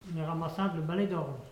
Langue Maraîchin
Locution